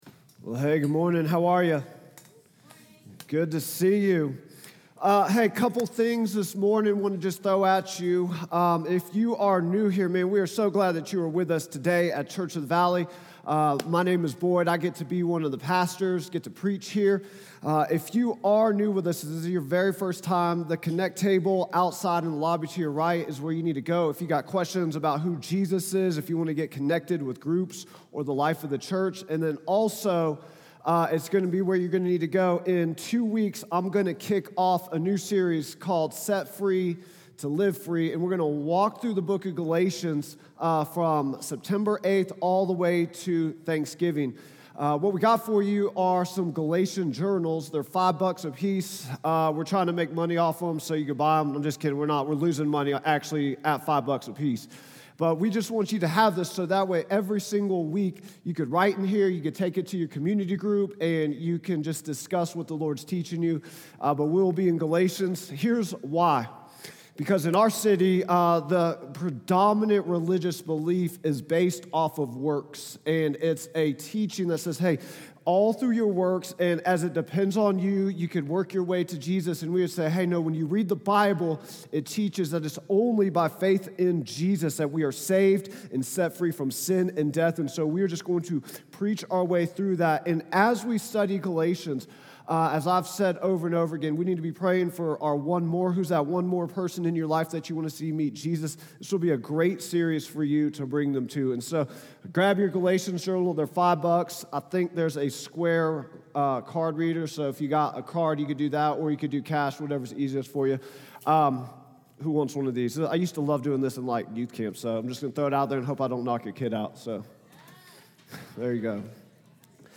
preaches on Matthew 16:24-25